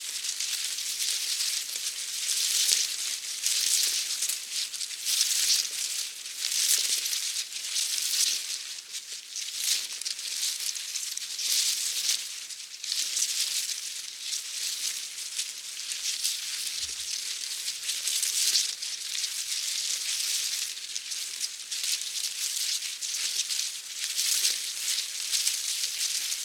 windbush_5.ogg